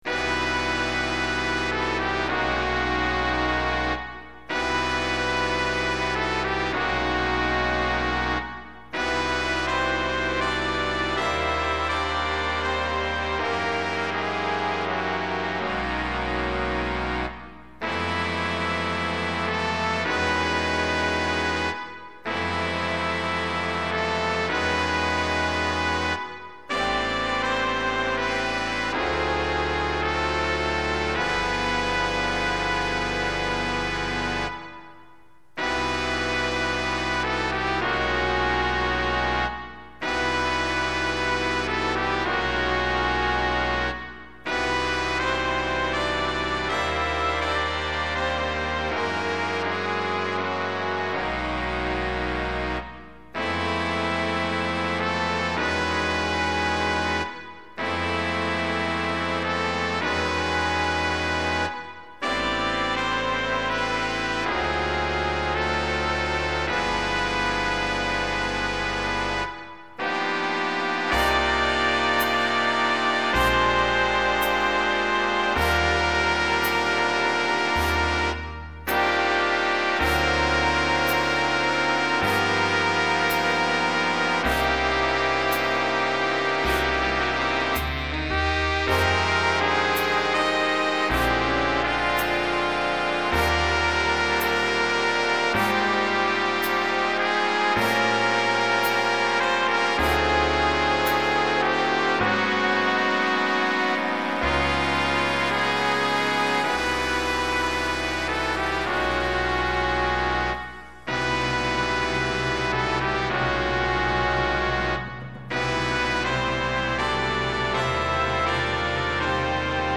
Big Band Arrangements